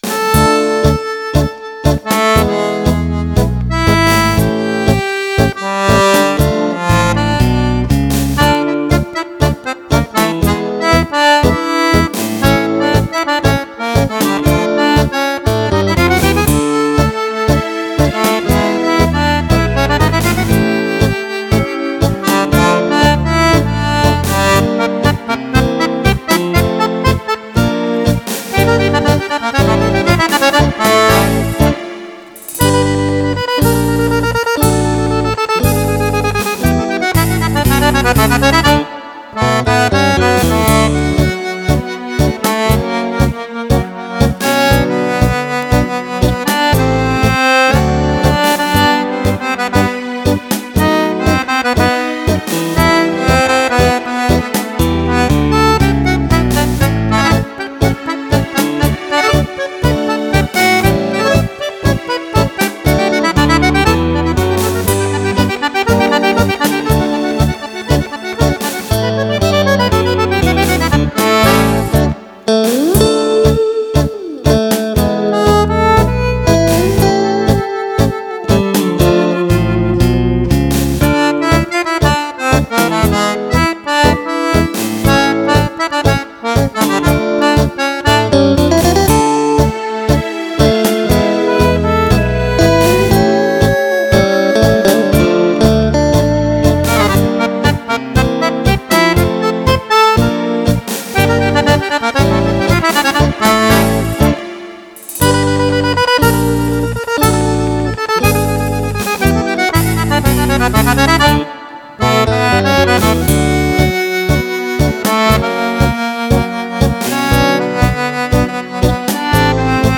Tango per Fisarmonica
Fisarmonica